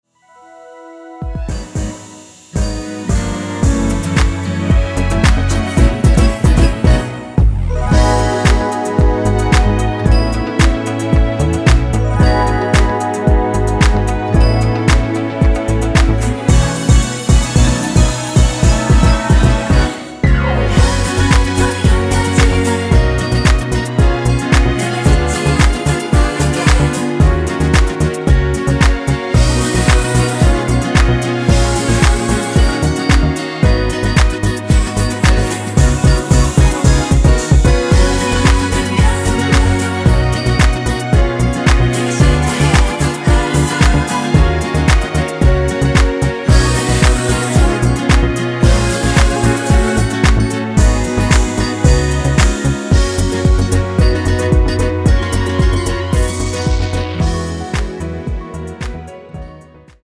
(-2) 내린 코러스 포함된 MR 입니다.(미리듣기 참조)
◈ 곡명 옆 (-1)은 반음 내림, (+1)은 반음 올림 입니다.
앞부분30초, 뒷부분30초씩 편집해서 올려 드리고 있습니다.